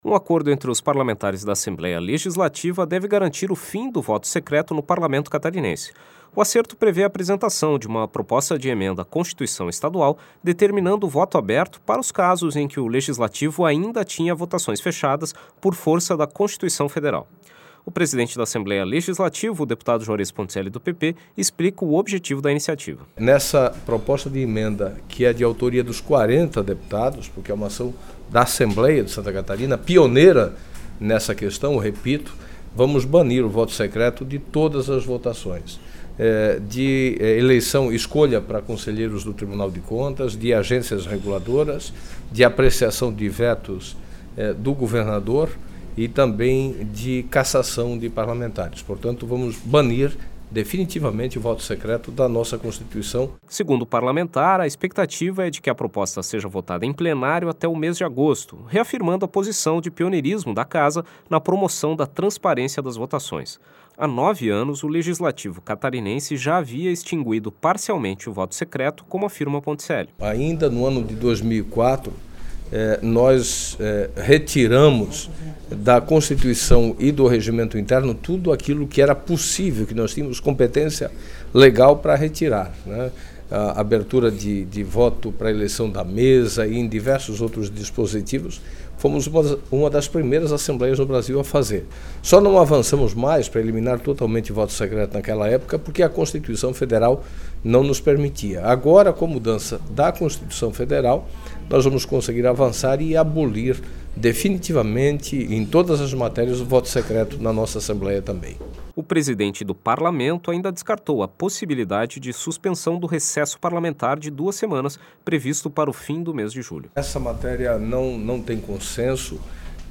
Entrevista com: deputado Joares Ponticelli (PP), presidente da Assembleia Legislativa.